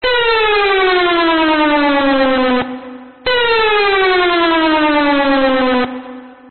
下降音のサイレン。
今回はアナログタイプのソフトシンセを使い、
オシレータで矩形波とノコギリ波をデチューンさせ、
そのピッチをADSRで下降させてます。
その音にオーバードライブをかけてスピーカーが割れた感じを出し、
リバーブをかけて質感を調整。
きいてみる(下降音)
なんとなく緊張感が感じられませんね(苦笑)。